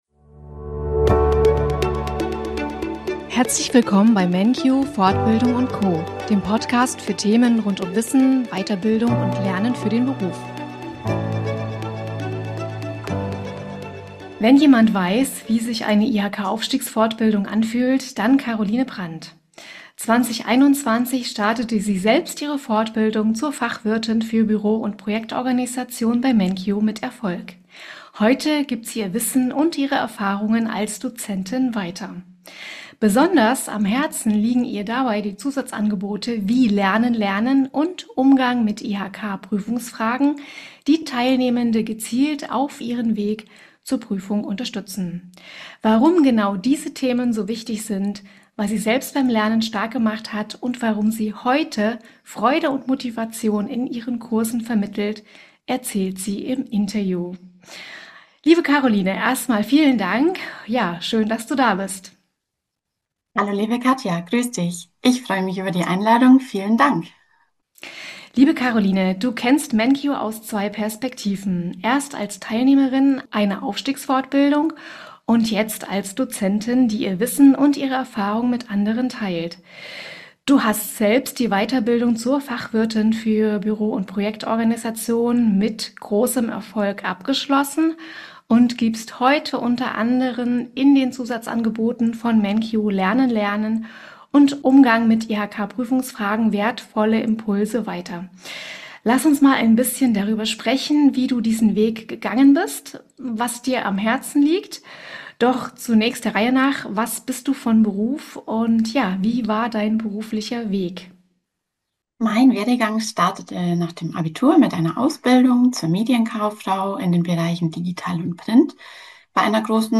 Im Interview teilt sie ihren Weg, ihre Motivation und wie sie andere beim Lernen unterstützt.